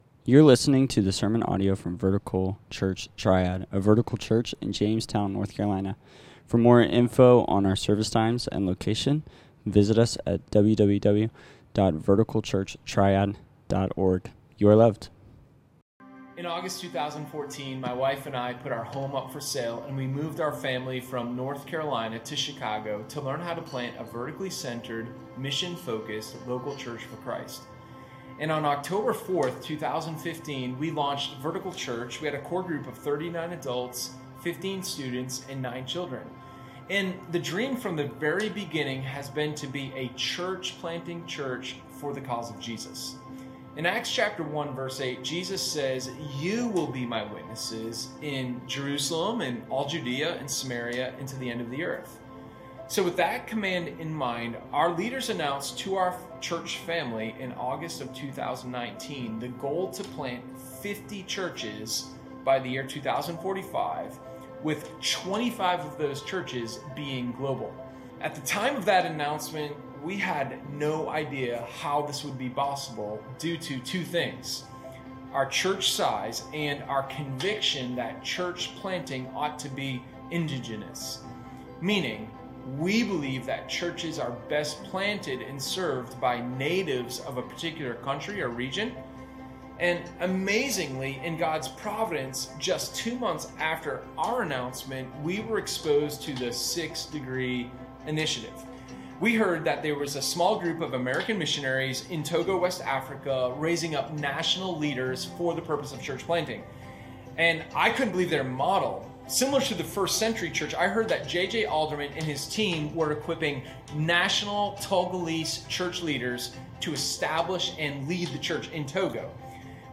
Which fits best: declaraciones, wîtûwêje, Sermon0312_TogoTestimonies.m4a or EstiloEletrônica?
Sermon0312_TogoTestimonies.m4a